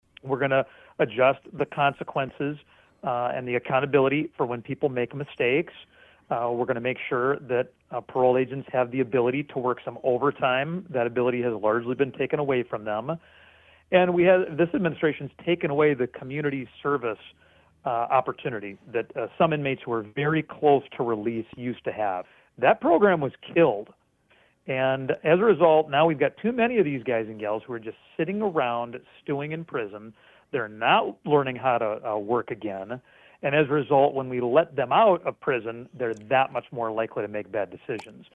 One aspect of that is the Supervision & Release Task Force.  We asked him what that is about.